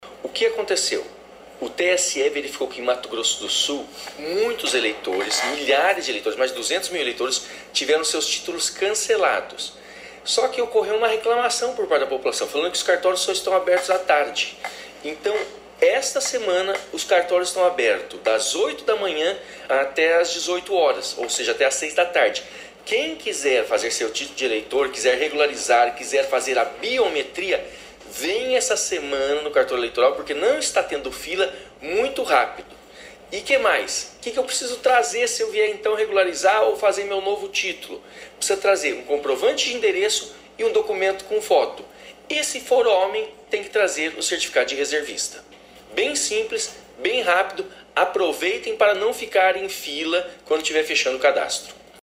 Em entrevista à FM Educativa MS o juiz auxiliar da Corregedoria e Vice-Presidência do Tribunal Regional Eleitoral de Mato Grosso do Sul (TRE-MS), Dr. Olivar Coneglian, este é um momento importante para que os eleitores verifiquem sua situação eleitoral e regularizem eventuais pendências.